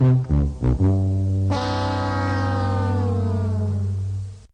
08 sfx FAIL HORN